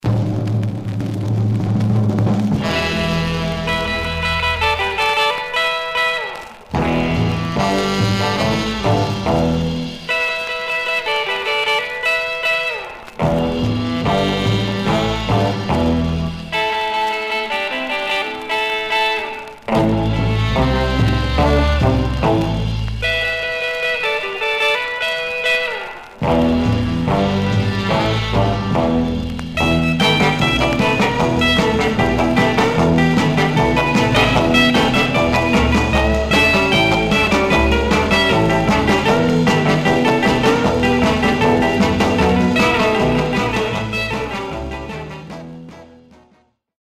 Surface noise/wear Stereo/mono Mono
R & R Instrumental